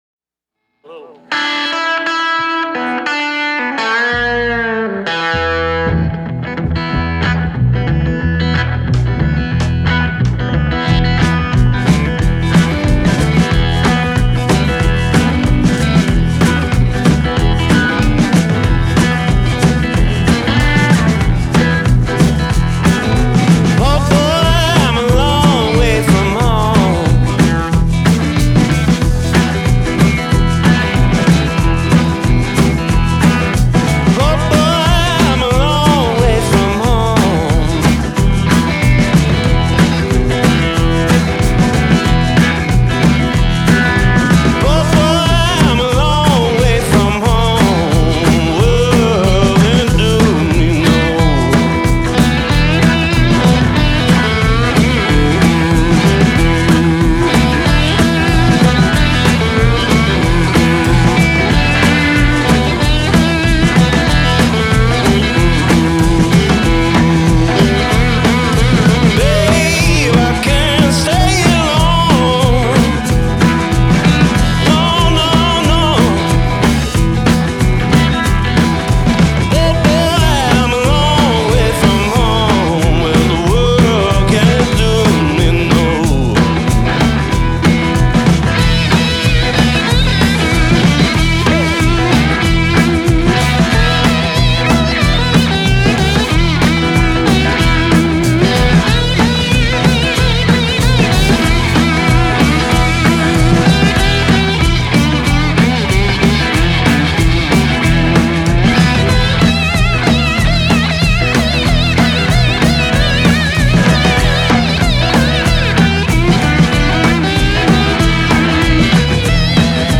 the pair have been prolific with their blues rock